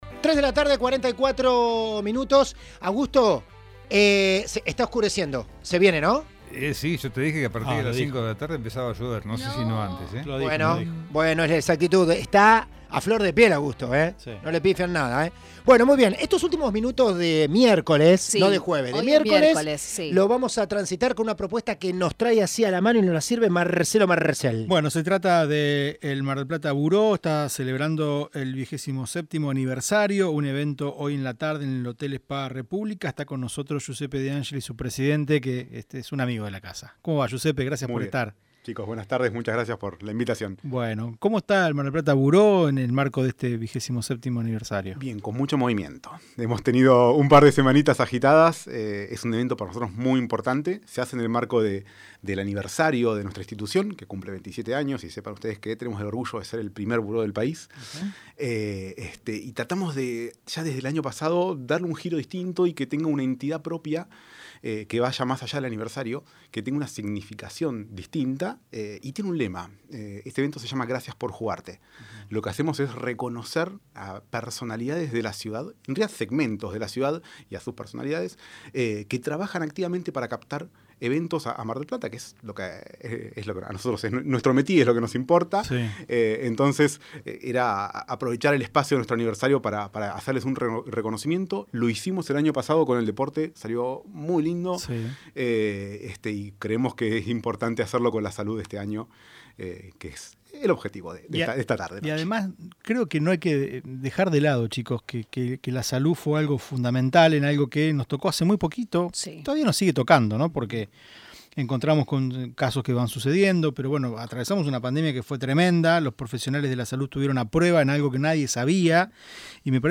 dialogó con el aire de UPM de Mitre Mar del Plata